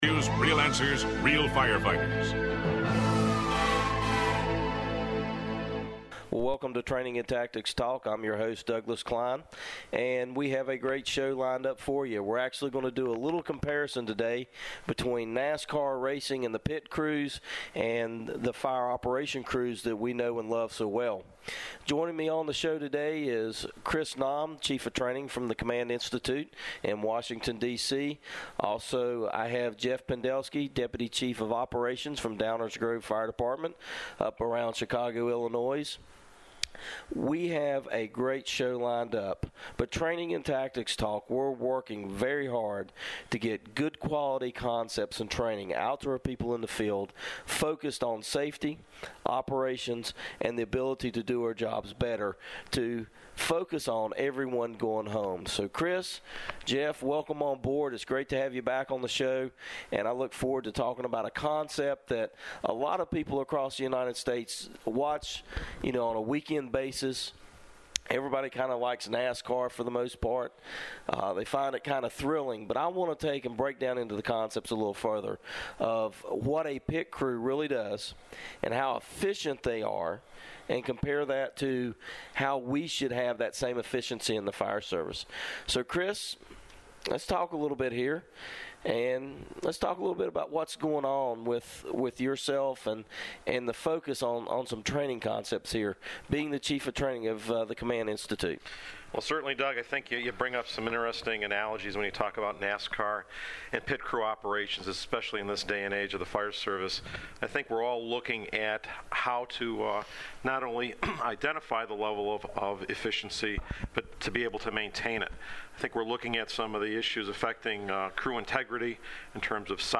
You will not want to miss these fire service leaders talking about the ways to enhance training and efficiency on the fireground while maintaining the paramount focus on firefighter safety.